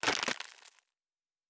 Plastic Foley 07.wav